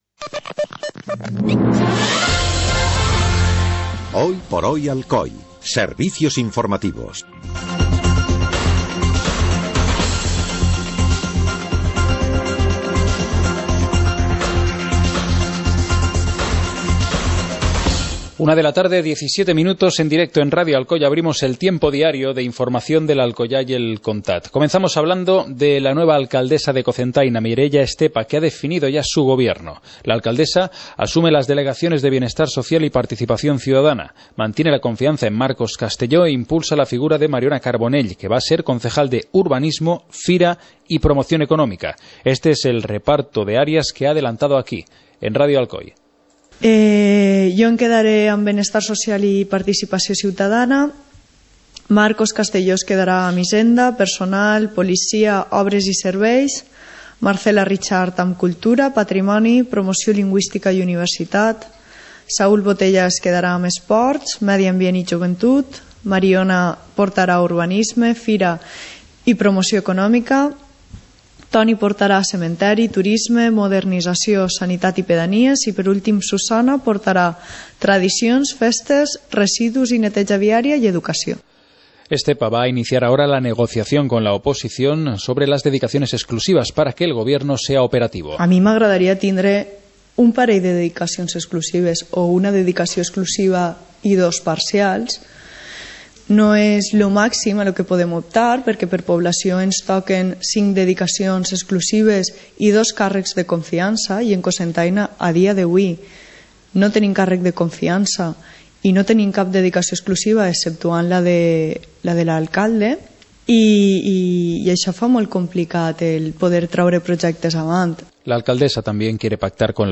Informativo comarcal - martes, 16 de junio de 2015